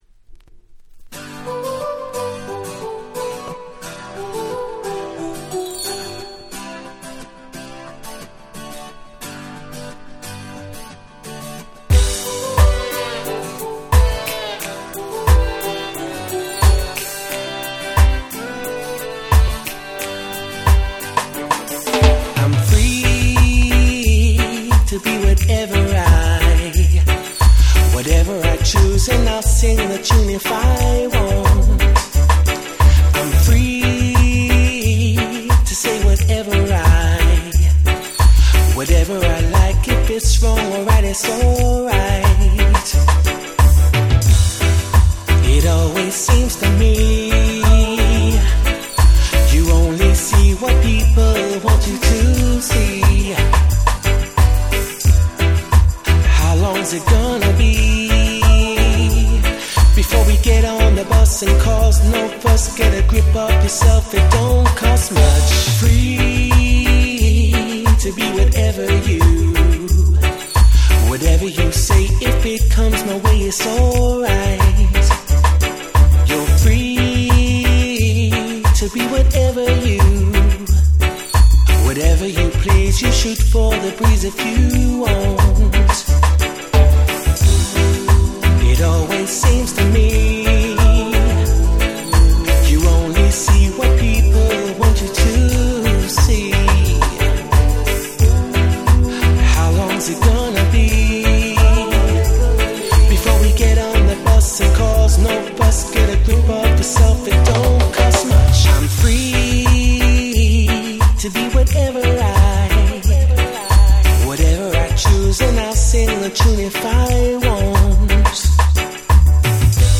08' Super Nice Cover Reggae !!